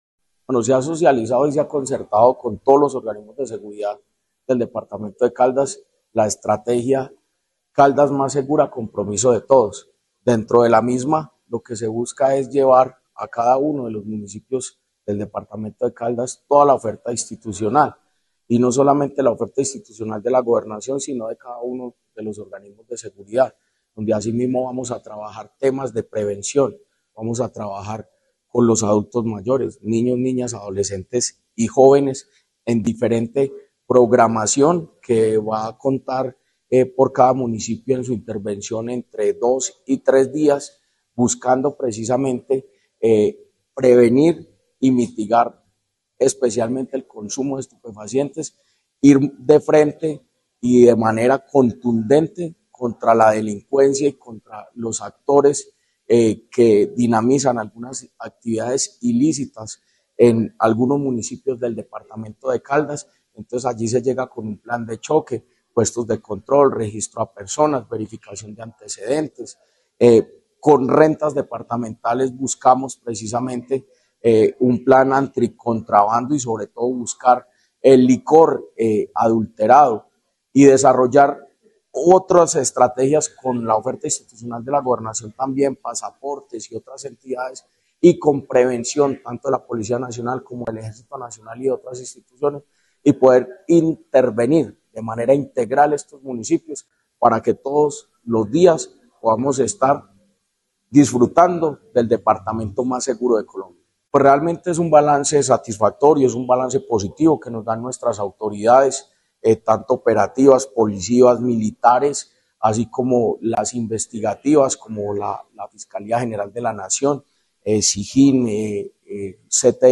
Jorge Andrés Gómez Escudero, secretario de Gobierno de Caldas.